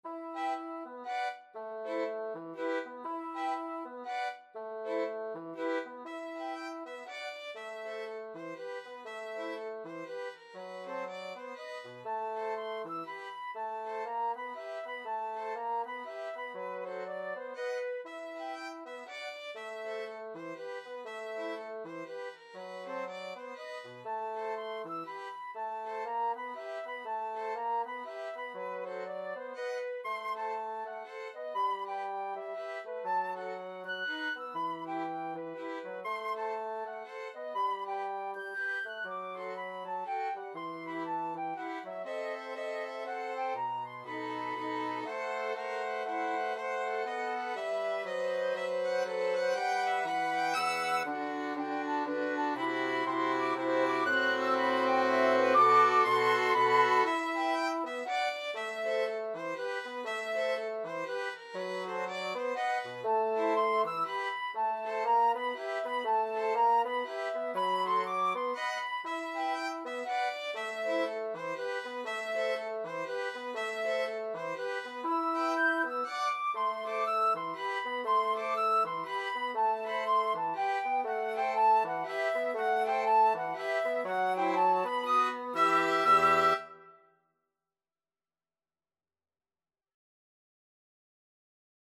Flute
Violin
Bassoon
3/4 (View more 3/4 Music)